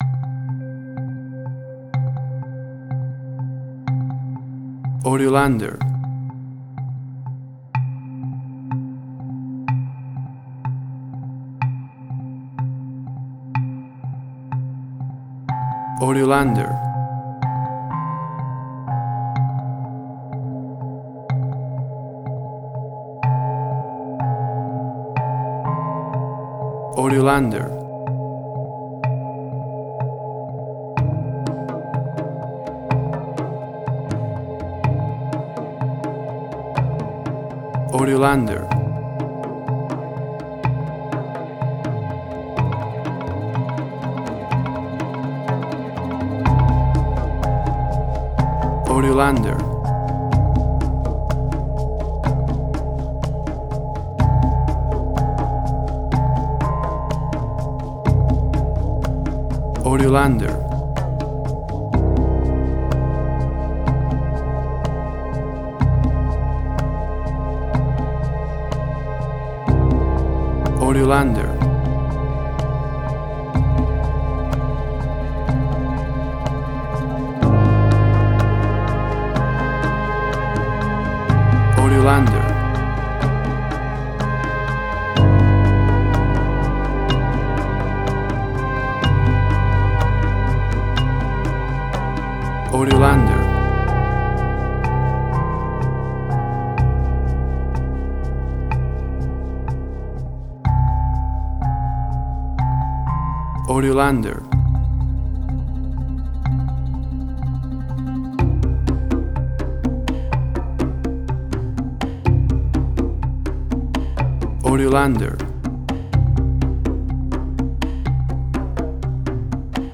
Tempo (BPM): 62